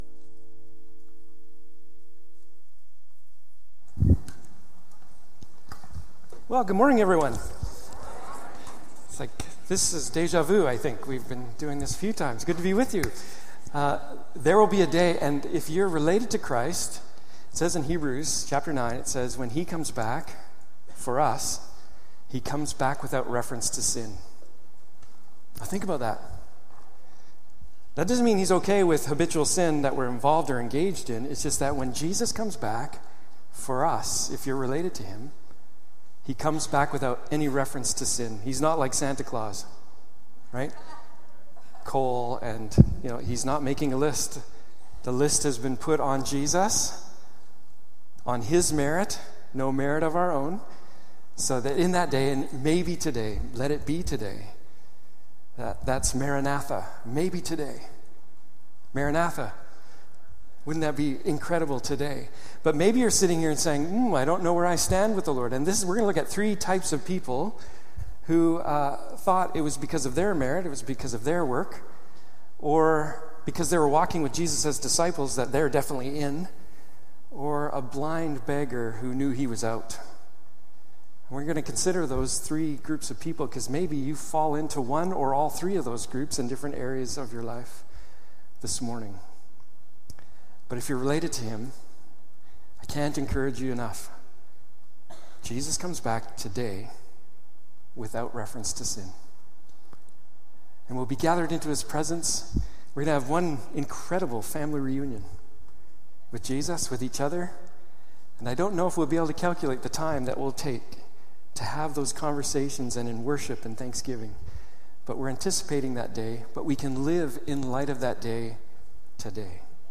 Gospel of Mark Passage: Mark 10:17-52 Service Type: Morning Service « Jesus